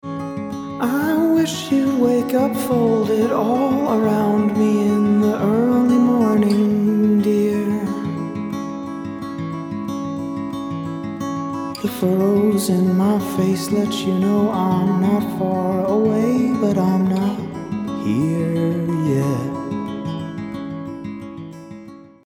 Note: In these examples, Version A is with no effect, Version B is with a medium decay length, and Version C is with a longer decay and more reverb applied.
Vocals-and-Guitar-EMT-Washy.mp3